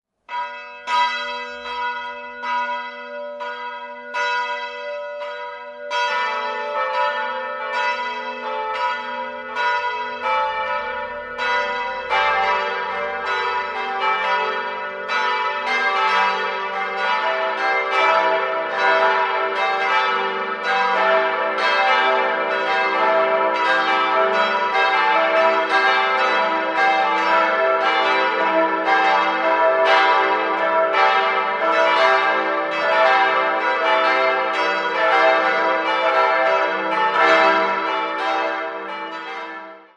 4-stimmiges ausgefülltes E-Moll-Geläute: e'-g'-a'-h' Die vier Glocken wurden im Jahr 1955 von Friedrich Wilhelm Schilling in Heidelberg gegossen und wiegen 1103, 589, 503 und 348 kg.